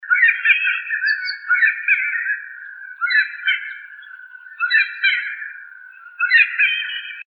Saracuruçu (Aramides ypecaha)
Nome em Inglês: Giant Wood Rail
Fase da vida: Adulto
Localidade ou área protegida: Reserva Ecológica Costanera Sur (RECS)
Condição: Selvagem
Certeza: Gravado Vocal